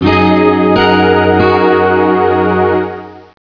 BriseLégère.wav